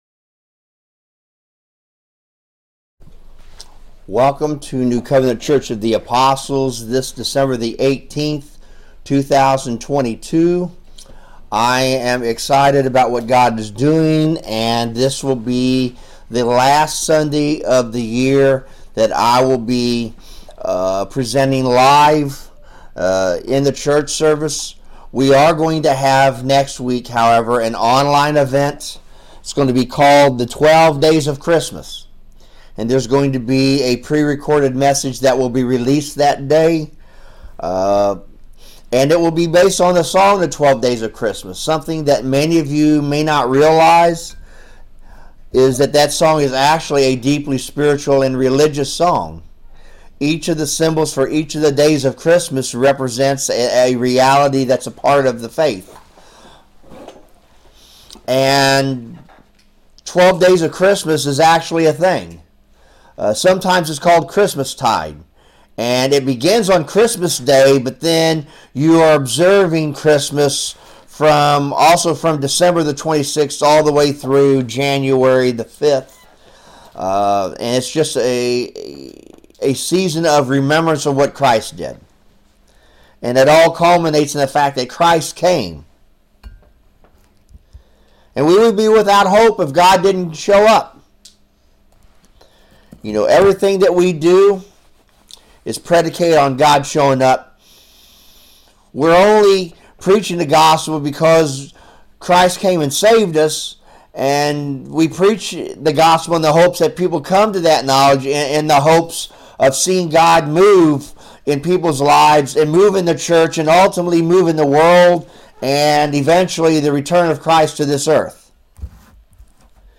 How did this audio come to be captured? Romans 15-16 Service Type: Sunday Service The study in Romans is concluding today.